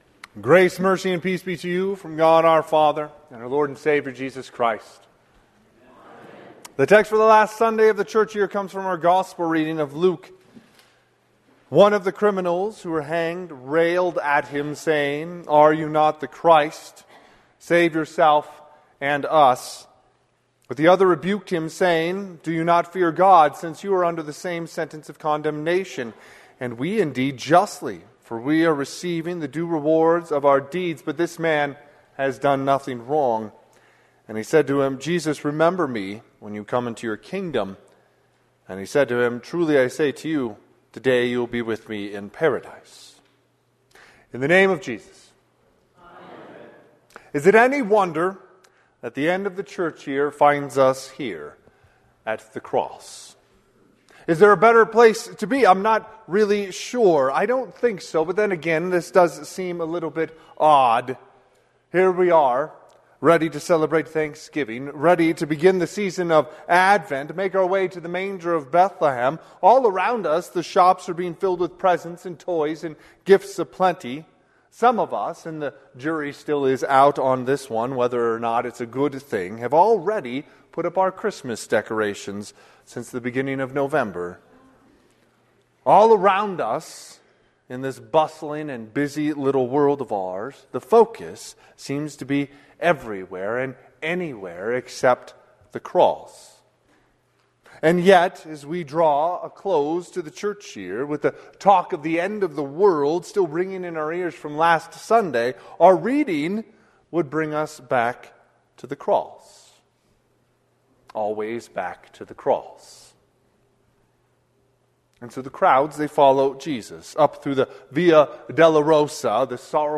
Sermon -11/23/2025 - Wheat Ridge Evangelical Lutheran Church, Wheat Ridge, Colorado
Last Sunday of the Church Year